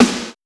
• 2000s Snare Drum Sample B Key 61.wav
Royality free snare sound tuned to the B note. Loudest frequency: 1402Hz